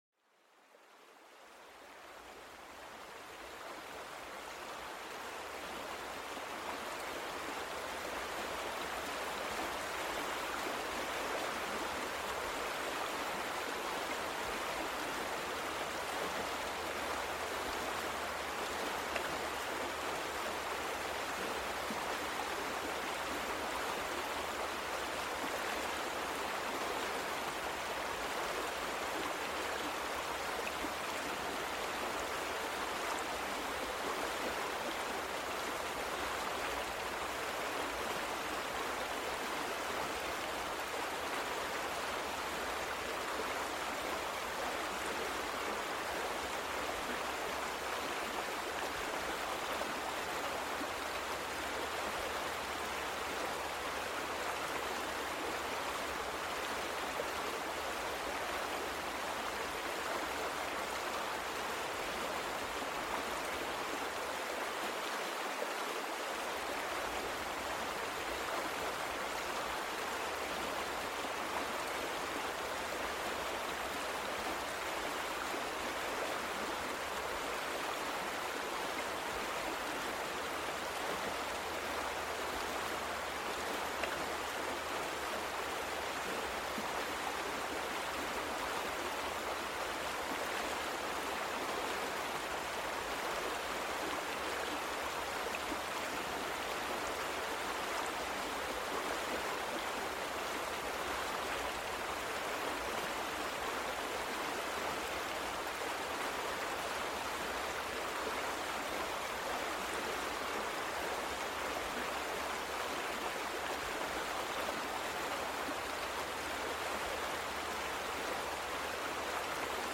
Sumérgete en el corazón de una pequeña cascada, donde el suave murmullo del agua fluyendo despierta una serenidad profunda. Déjate arrullar por el sonido calmante del agua deslizándose sobre rocas musgosas, un verdadero bálsamo para el alma.